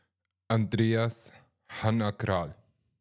Wie spricht man eigentlich den Namen richtig aus,